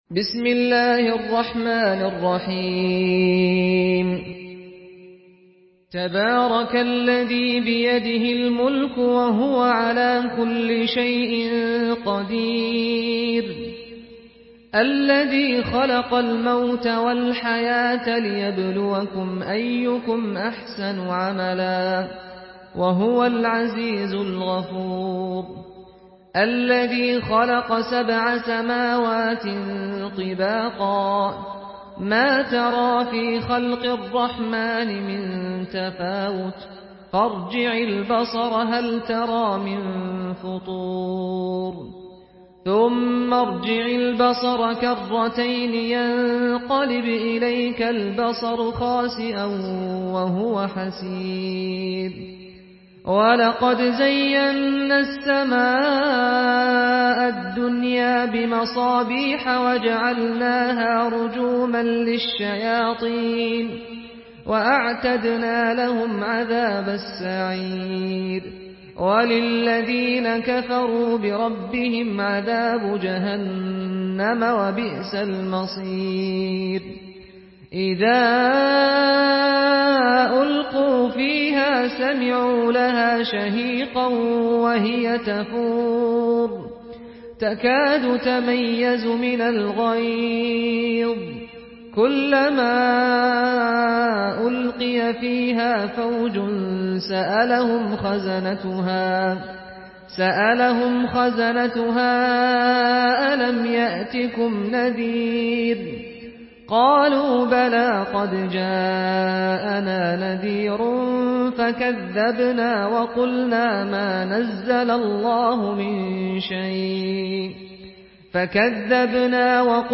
Surah আল-মুলক MP3 in the Voice of Saad Al-Ghamdi in Hafs Narration
Surah আল-মুলক MP3 by Saad Al-Ghamdi in Hafs An Asim narration.
Murattal Hafs An Asim